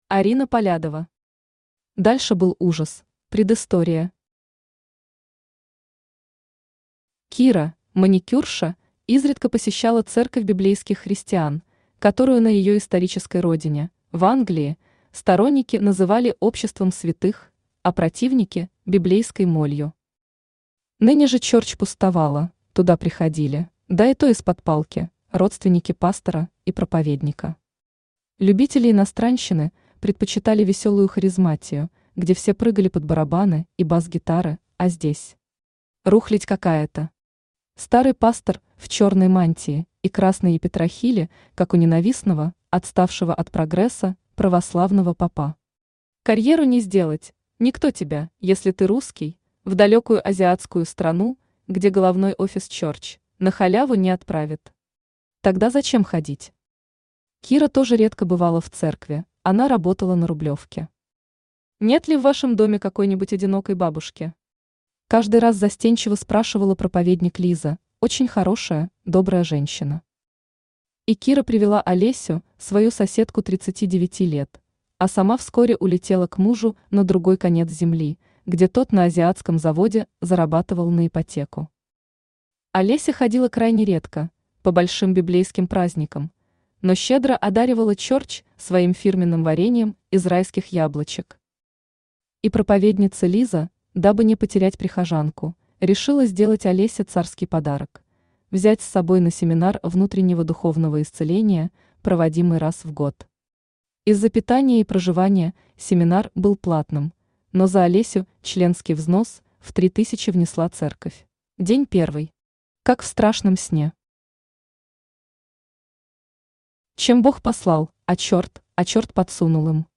Аудиокнига Дальше был ужас | Библиотека аудиокниг
Aудиокнига Дальше был ужас Автор Арина Полядова Читает аудиокнигу Авточтец ЛитРес.